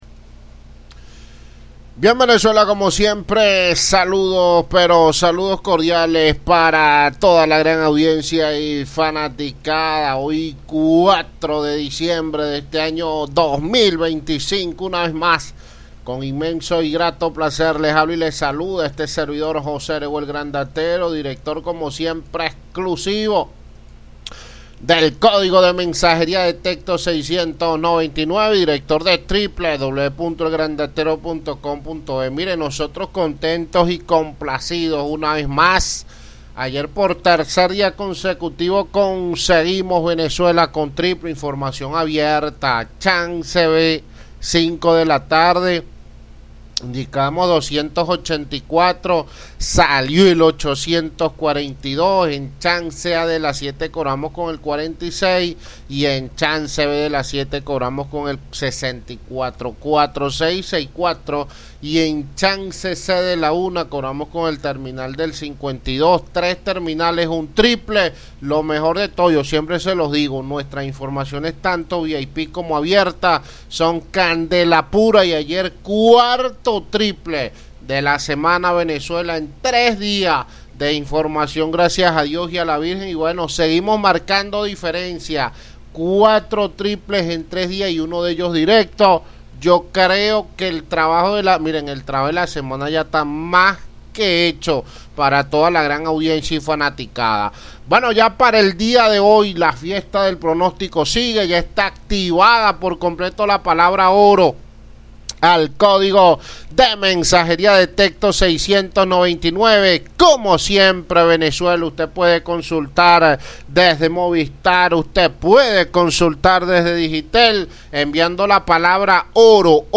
Programa radial de El Grandatero con análisis y pronósticos. Zulia, Zamorano, Chance y más.